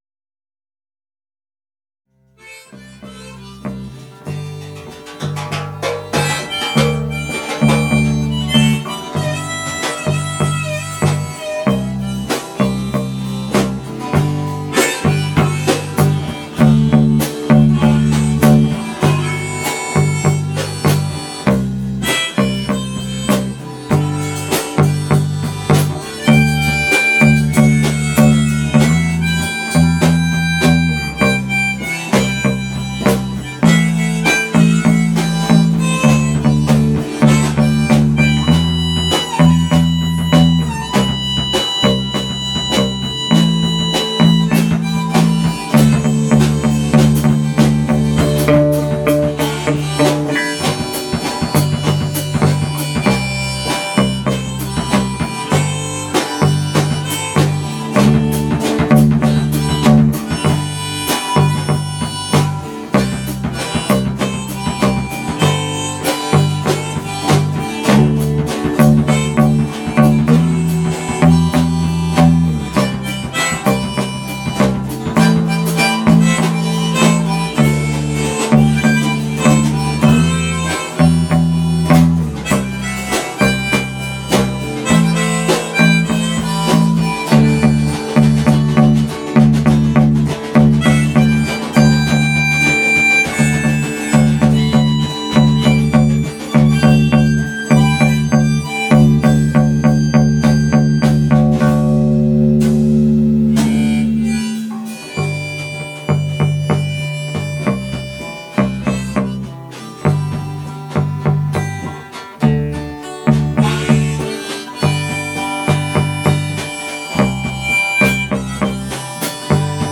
"Deborah Ann (Instrumental)"